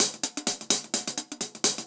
Index of /musicradar/ultimate-hihat-samples/128bpm
UHH_AcoustiHatC_128-01.wav